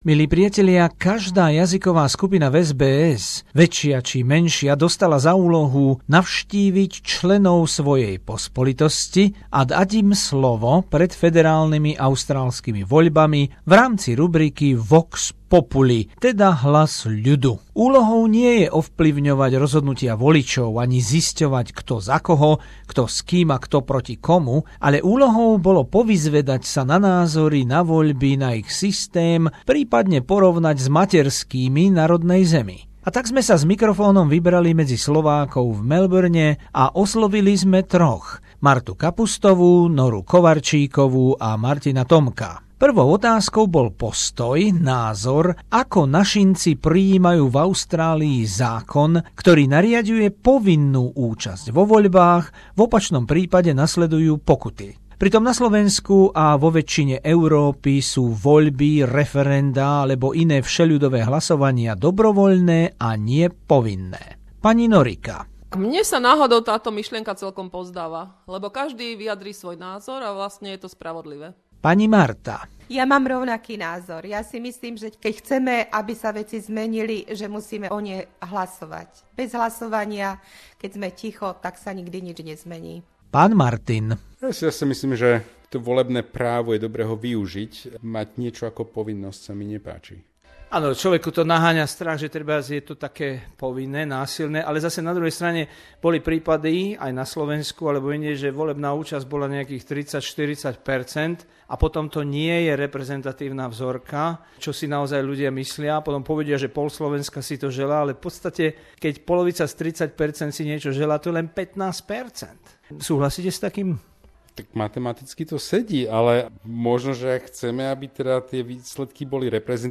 Anketa medzi členmi slovenskej pospolitosti v Melbourne týždeň pred federálnymi voiľbami 2016 v rámci série VOX Populi - Hlas ľudu